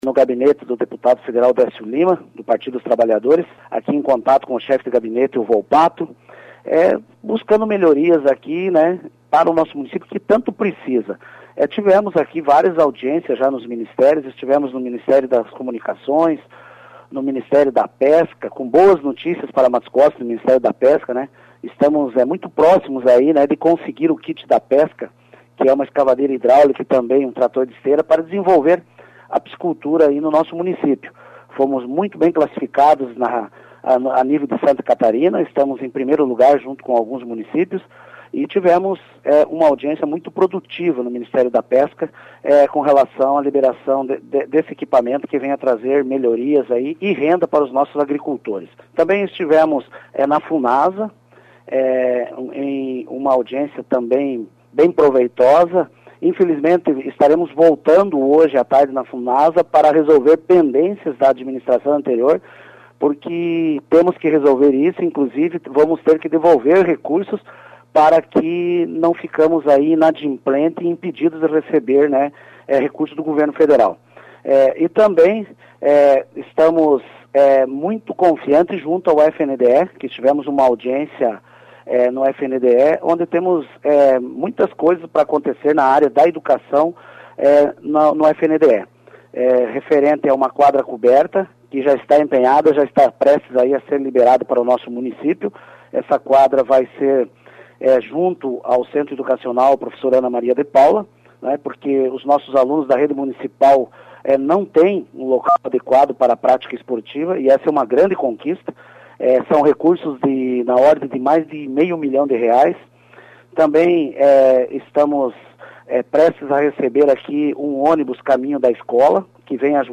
Acompanhe as palavras do prefeito em um breve resumo de sua visita.